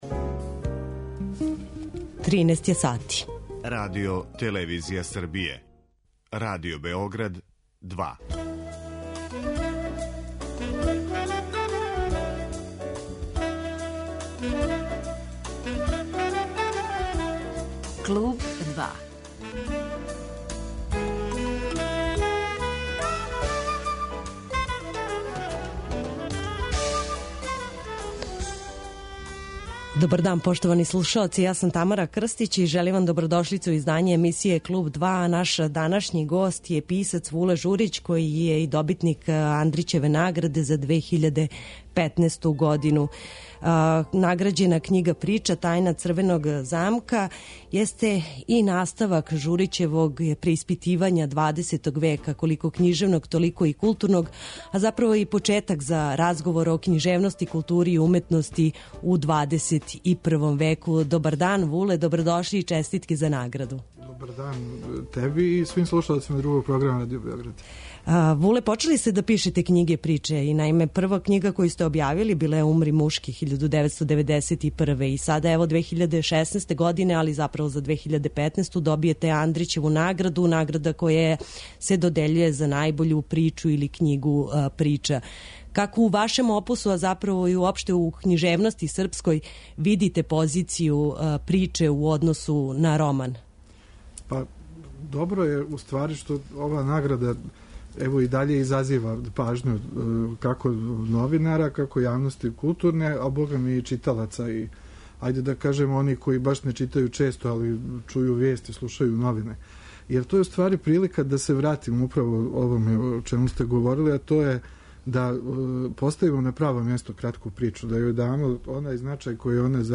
Вуле Журић, добитник Андрићеве награде за 2015. годину, гост је емисије 'Клуб 2'.